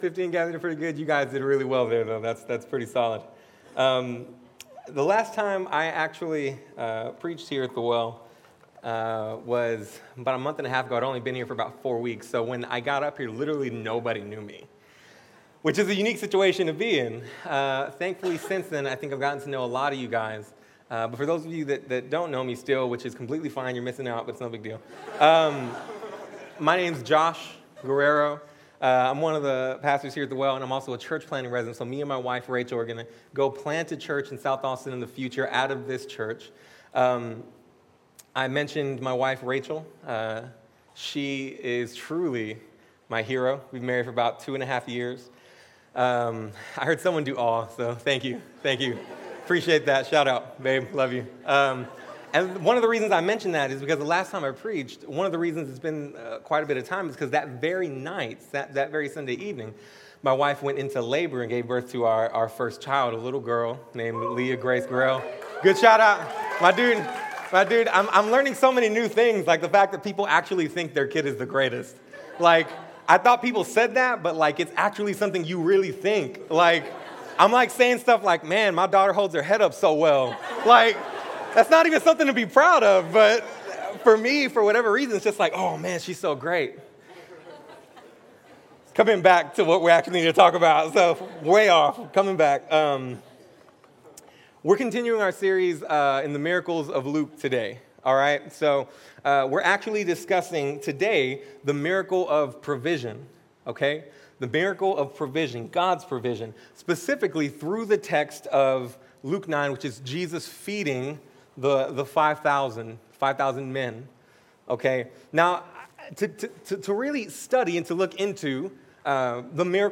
In this sermon we look at the miraculous multiplication of fish and loaves of bread to feed 5000.